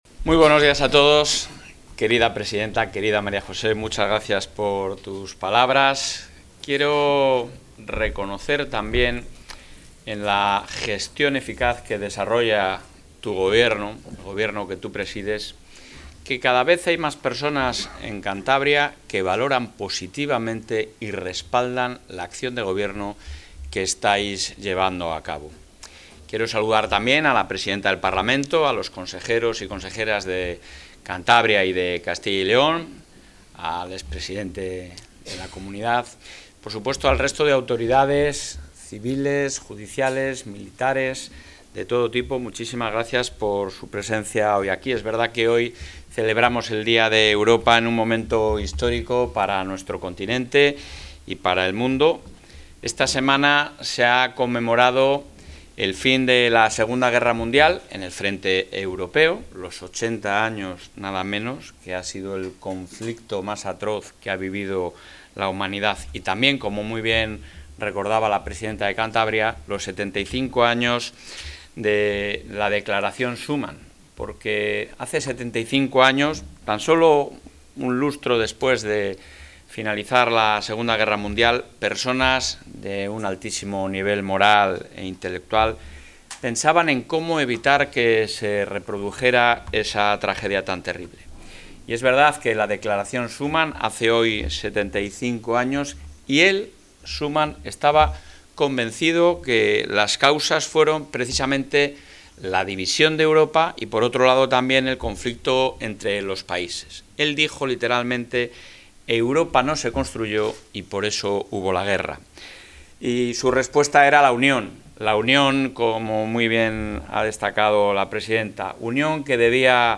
Intervención del presidente de la Junta.
El presidente de la Junta de Castilla y León, Alfonso Fernández Mañueco, ha asistido hoy en Santander, junto a la presidenta de Cantabria, María José Sáenz de Buruaga, al acto conmemorativo del Día de Europa, coincidiendo con el 75 aniversario de la Declaración Schuman.